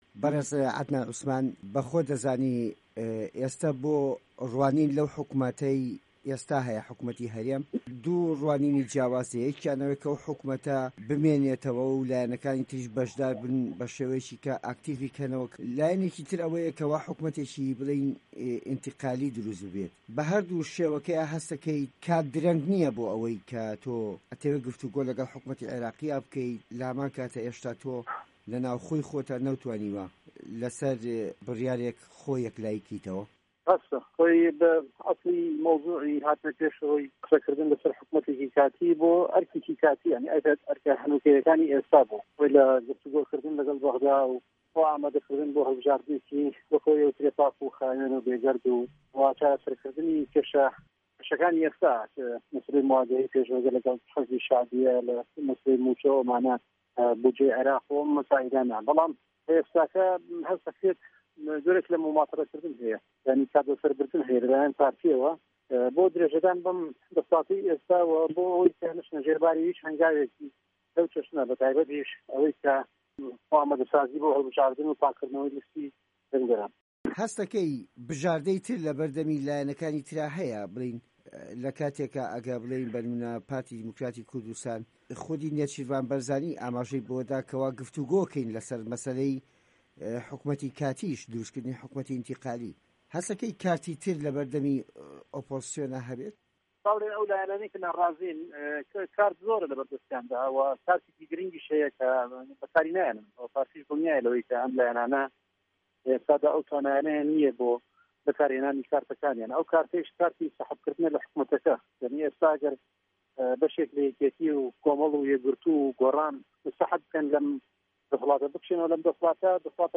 وتووێژی عدنان عوسمان